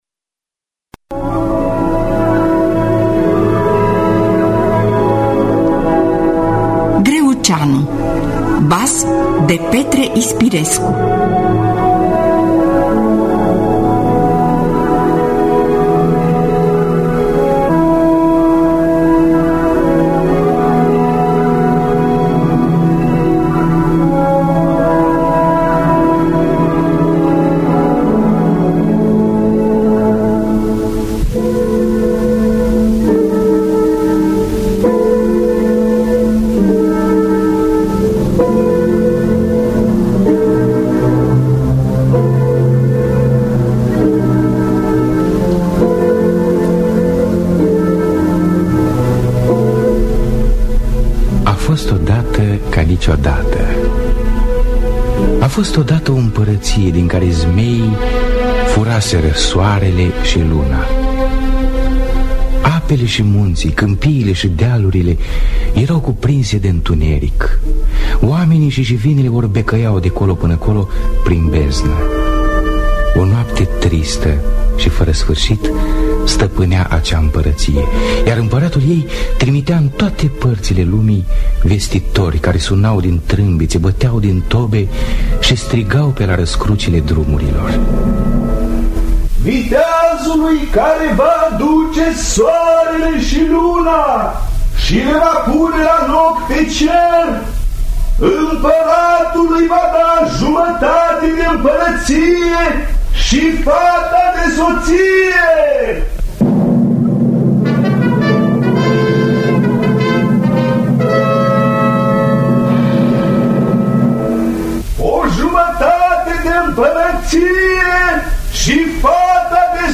Greuceanu de Petre Ispirescu – Teatru Radiofonic Online